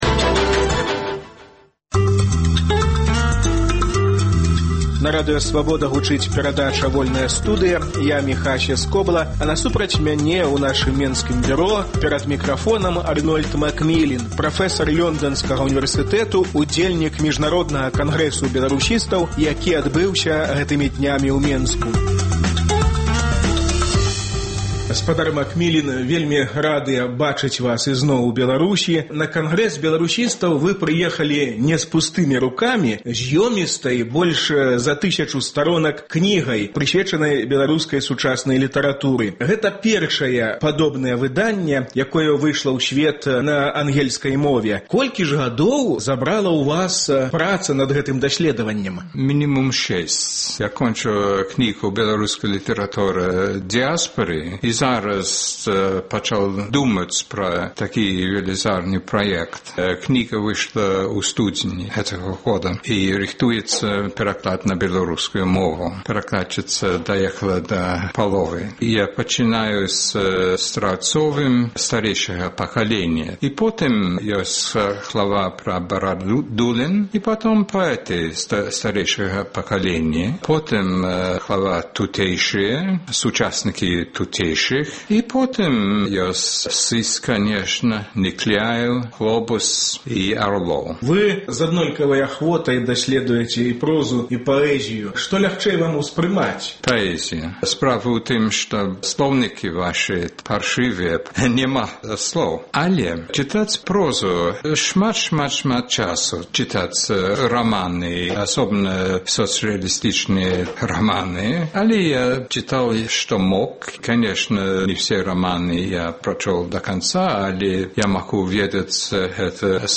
Беларускі літаратурны пантэон: погляд зь Лёндану. Гутарка